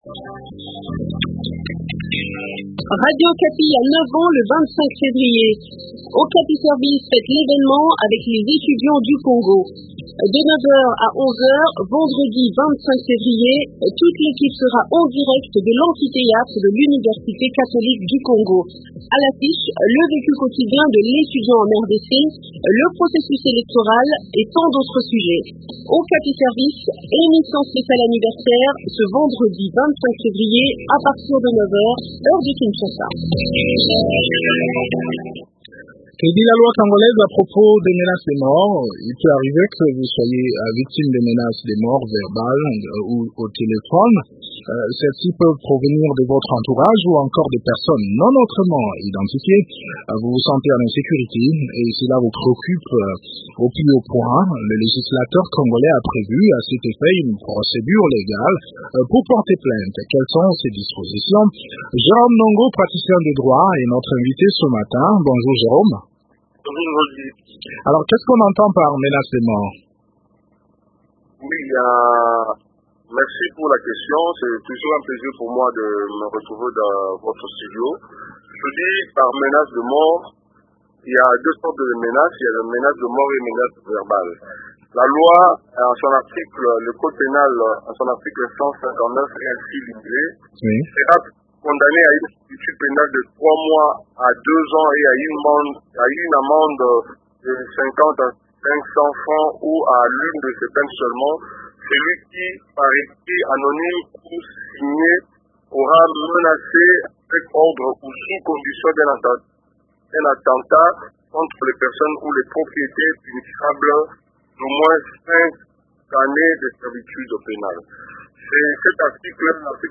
Eléments de réponse dans cet entretien que